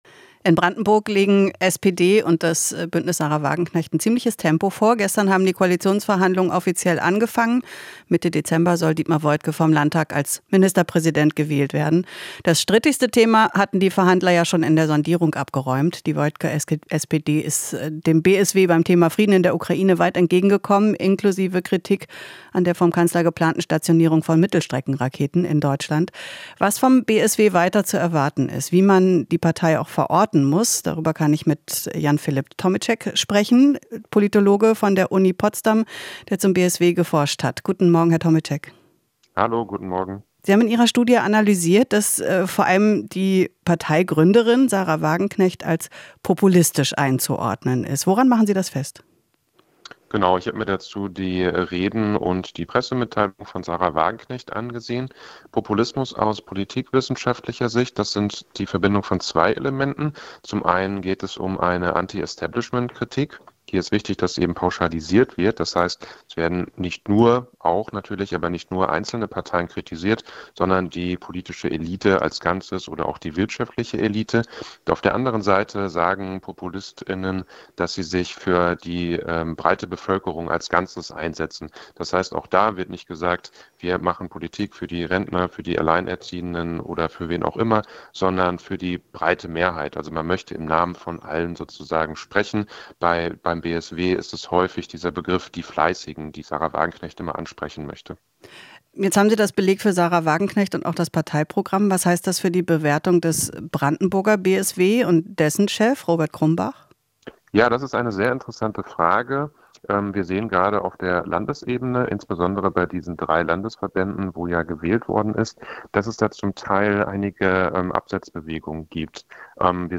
Interview - Politologe: Brandenburger SPD und BSW mit Schnittmengen